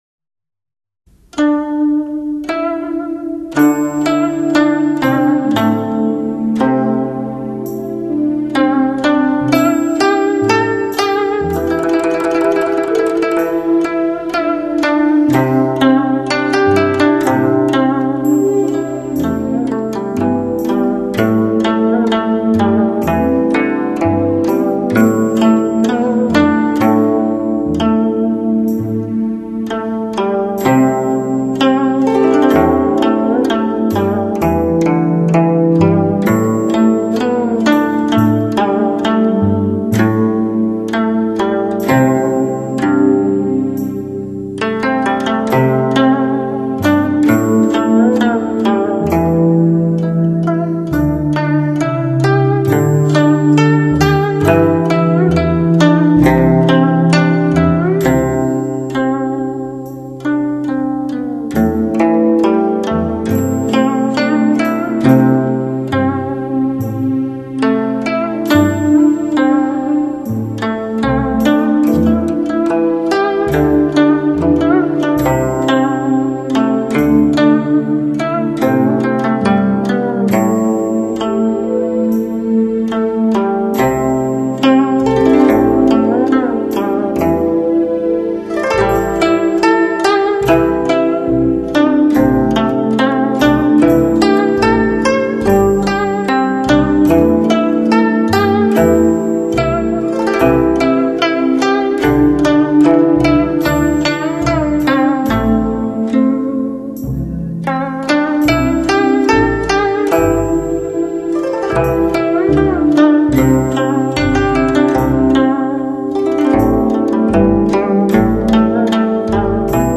民間經典名曲集  [ WAV分軌 5.1聲道 ]
專輯歌手：純音樂